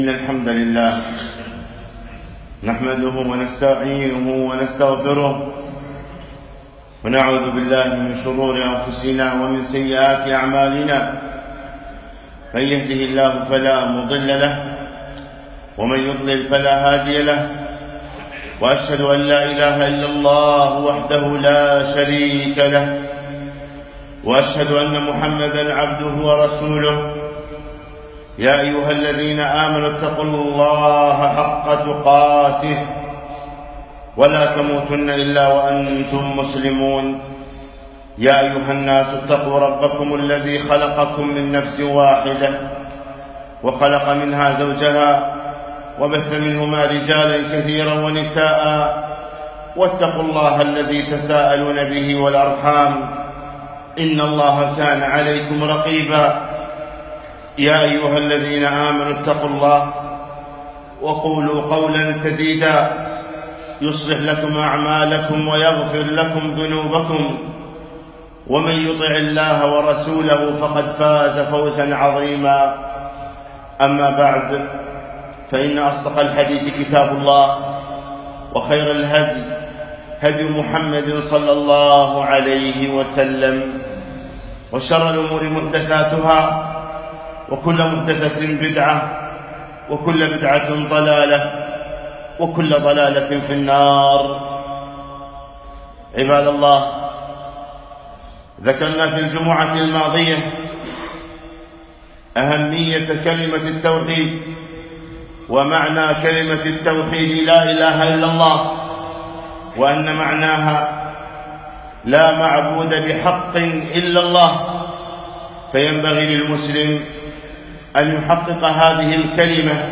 خطبة - شروط لا إله إلا الله